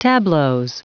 Prononciation du mot tableaux en anglais (fichier audio)
Prononciation du mot : tableaux